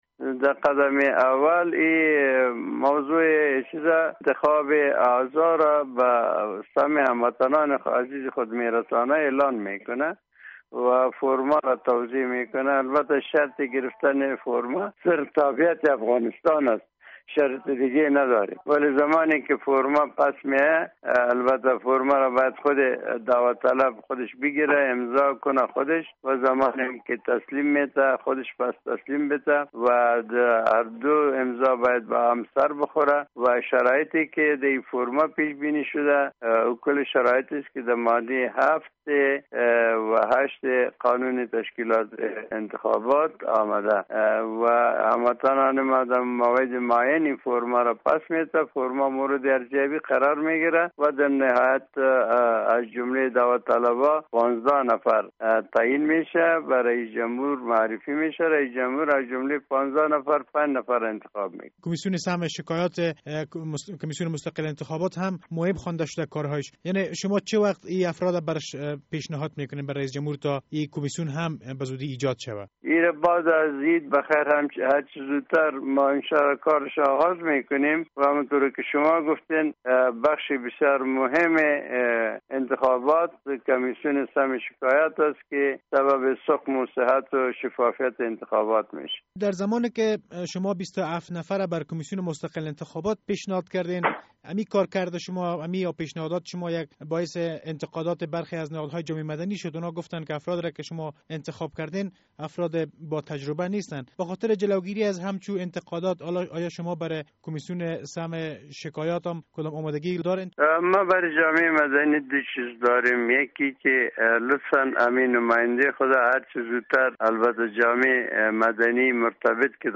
مصاحبه در مورد معرفی اعضای کمیسیون سمع شکایات انتخابات به حامد کرزی